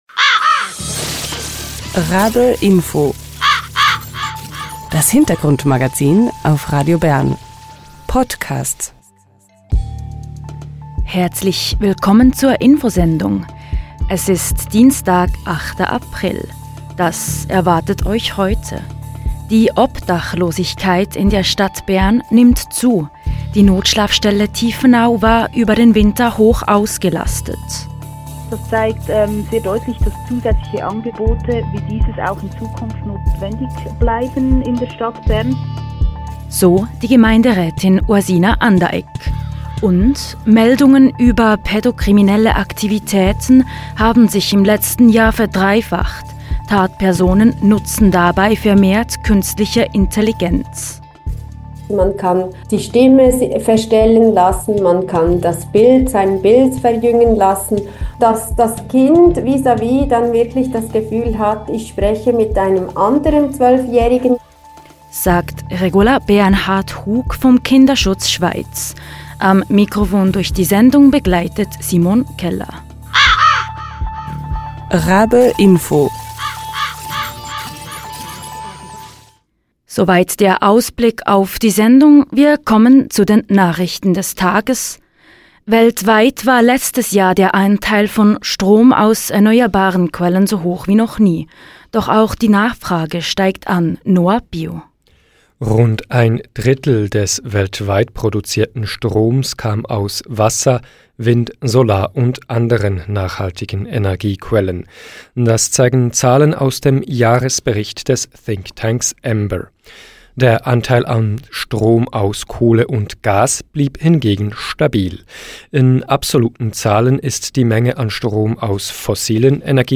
Die Notschlafstelle Tiefenau war über den Winter hoch ausgelastet. Die Gemeinderätin Ursina Anderegg spricht im RaBe Info über die Zunahme der Obdachlosigkeit in der Stadt Bern.